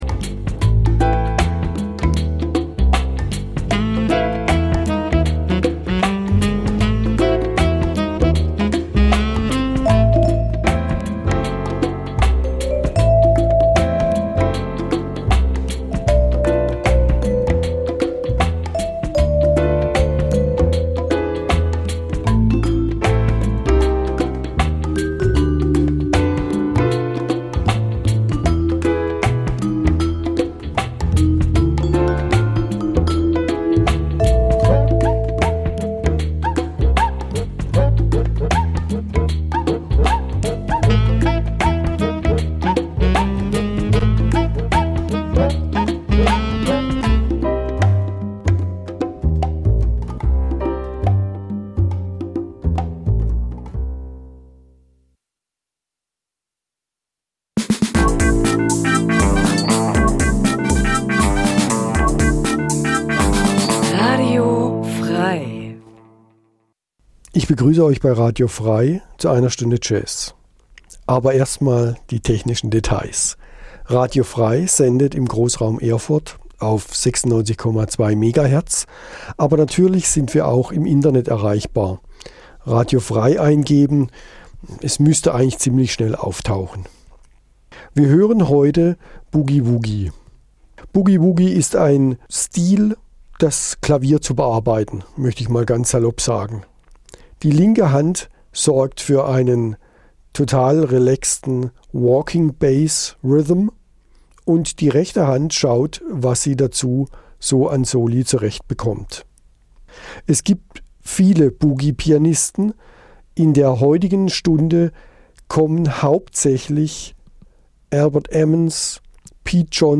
Boogie-Woogie - am Klavier 2 (circa 1940)
Eine Stunde Jazz Dein Browser kann kein HTML5-Audio.